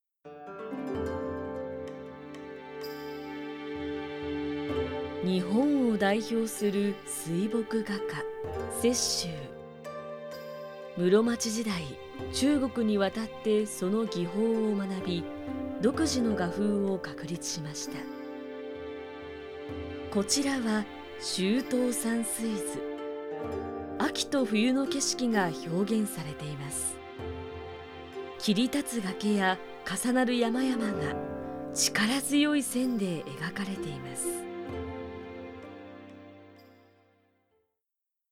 ボイスサンプル
番組ナレーション